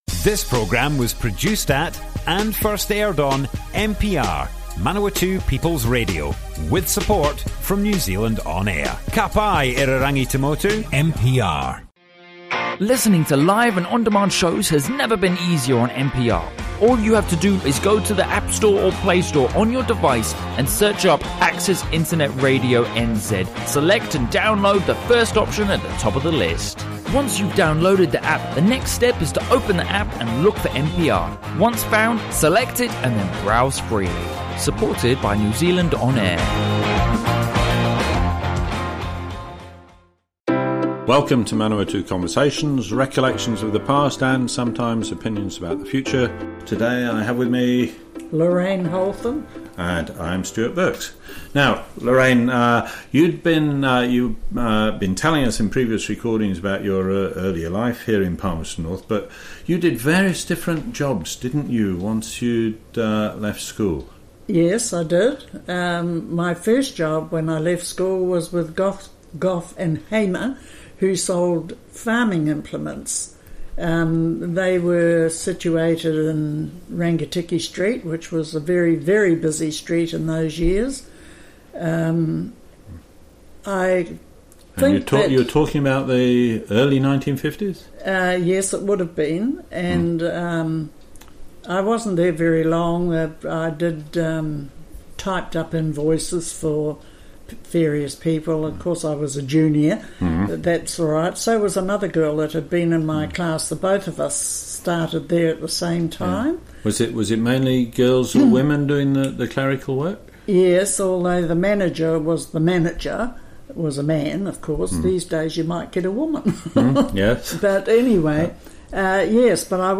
Manawatu Conversations More Info → Description Broadcast on Manawatu People's Radio 19th March 2019.
oral history